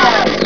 game-source/ParoxysmII/sound/weapons/sawoff.wav at a86c777218c3b32c2c21d78278b1f38d56380ce7
sawoff.wav